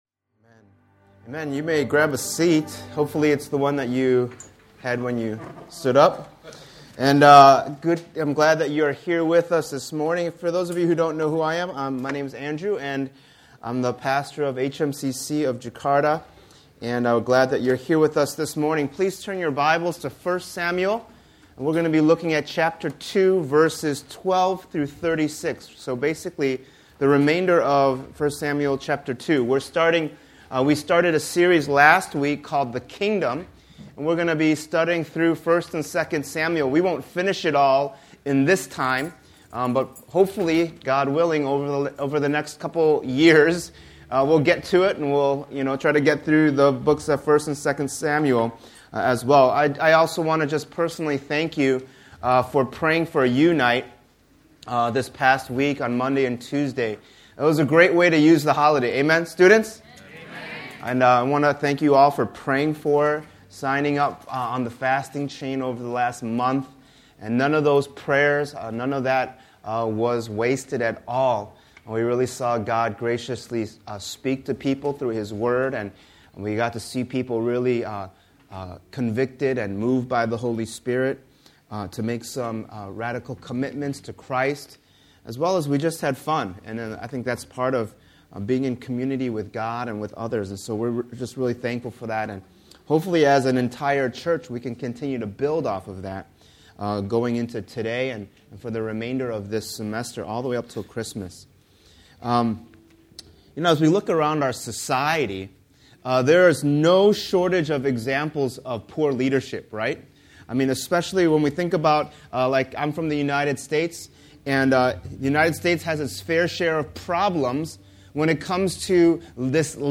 Download Audio Subscribe to Podcast Audio The Kingdom Series This sermon series called “The Kingdom” will go through the books of 1 and 2 Samuel.